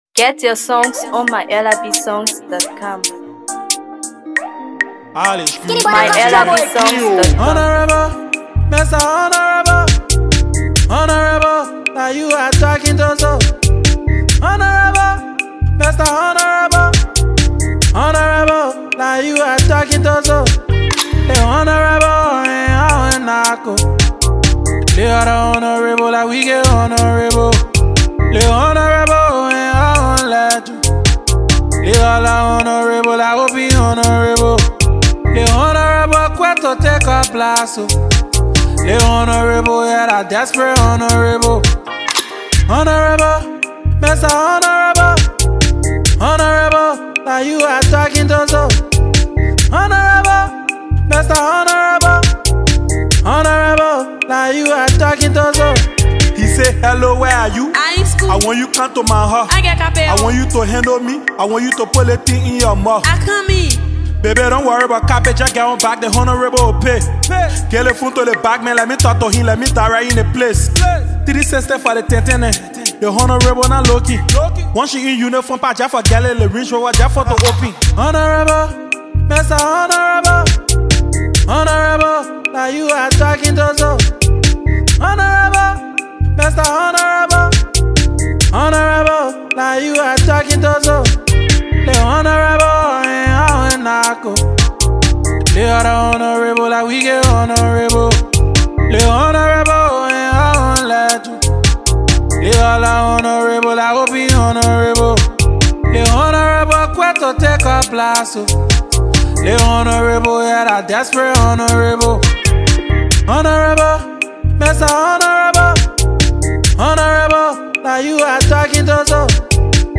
Afro PopMusic
Amazing Freestyle Banger